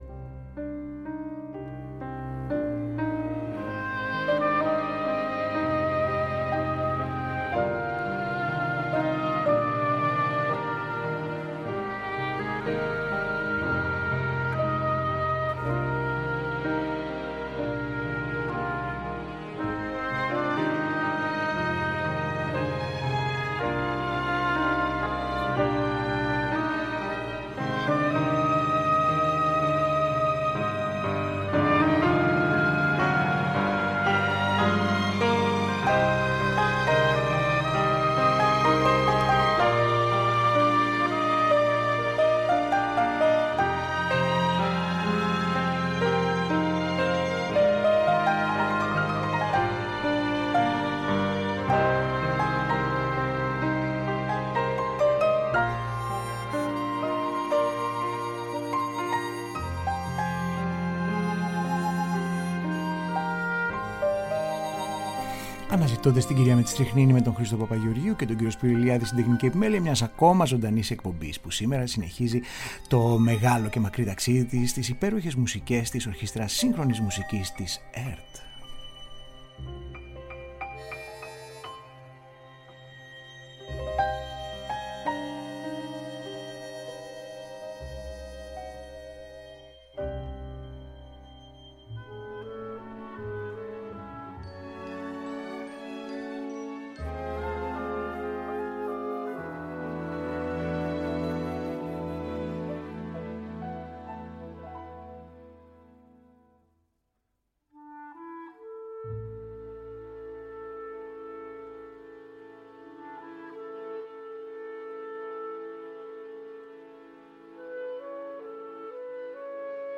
Η Ορχήστρα «ελαφράς» – «ποικίλης» και «σύγχρονης» Μουσικής της ΕΡΤ έτσι όπως έχει μετονομαστεί μέσα στις δεκαετίες αποδεικνύει ότι παραμένει σύντροφος μας στις πιο ευχάριστες ανάλαφρες και δημοφιλείς μουσικές στιγμές της ραδιοφωνίας και τηλεόρασης μέσα από ένα μουσικό οδοιπορικό με επιλεγμένες ηχογραφήσεις από το ποικίλο και αστείρευτο αρχείο της.
Ως επί το πλείστον θα ακουστούν κινηματογραφικές – αλλά και άλλες παρόμοιου ύφους – μουσικές, που πιστοποιούν την μακρά παράδοση και προσφορά στο καθημερινό κοινωνικό και πολιτιστικό γίγνεσθαι της πατρίδας μας από μια ορχήστρα που πάντα ανανεώνεται και δεν χάνει ποτέ τόσο το εκλεκτό γούστο όσο και το κέφι της!